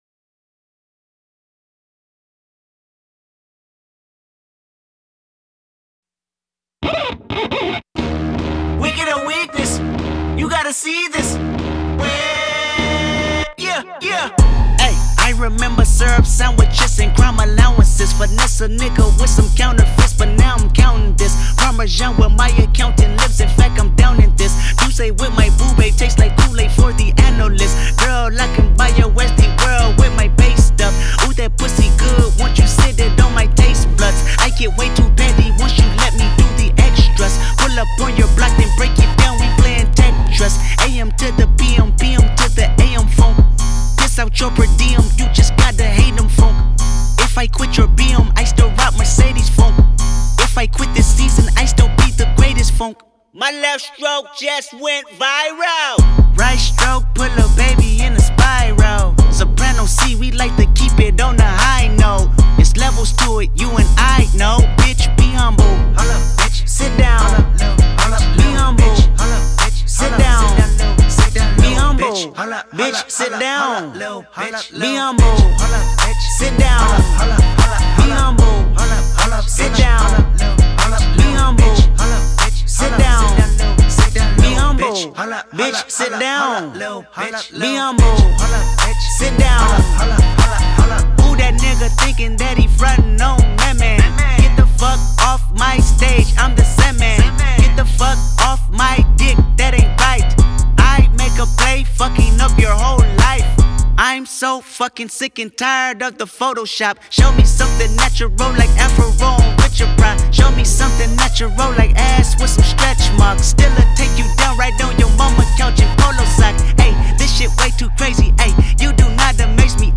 موسيقى رعب